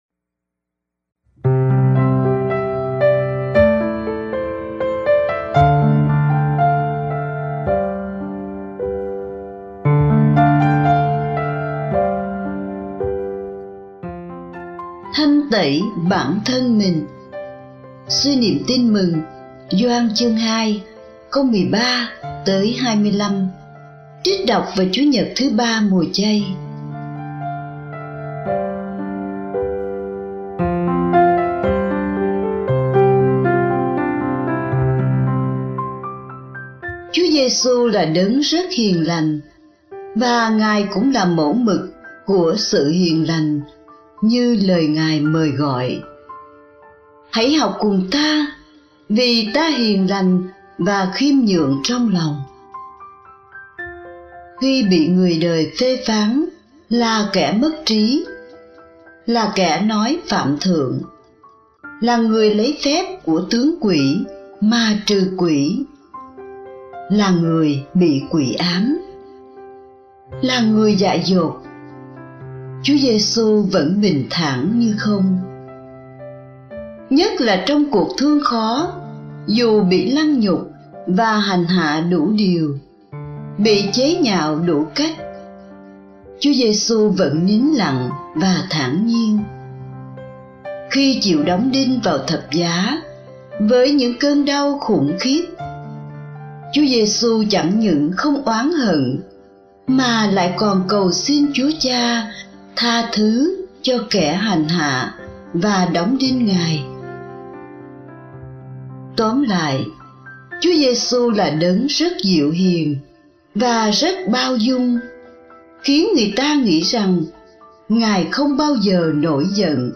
(Suy niệm Tin mừng Gioan (2, 13-25) trích đọc vào Chúa nhật 3 Mùa Chay)